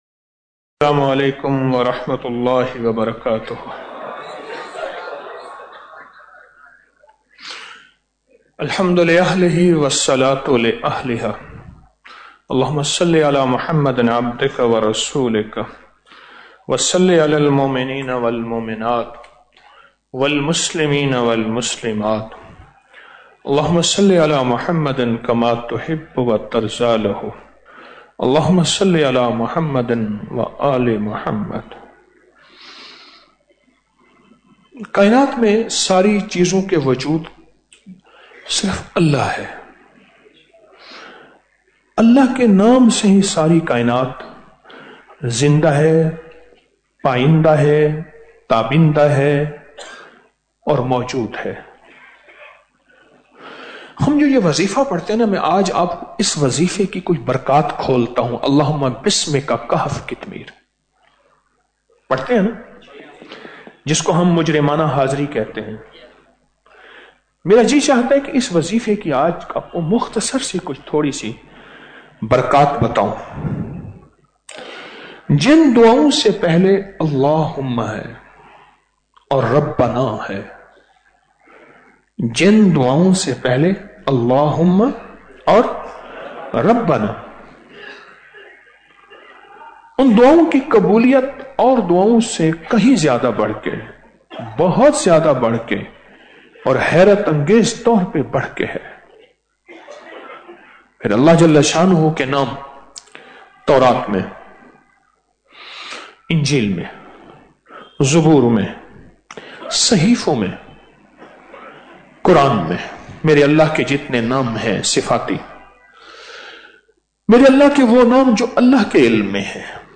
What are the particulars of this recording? Speeches about 14 Ramadan After Salat Ul Taraweeh 14 March 2025. The talk is aimed to draw the Ummah closer to Allah Subhan O Wa Tallah and to provide the Ummah with a solution to their world and hereafter through Quran, Sunnah and practices of the faithful.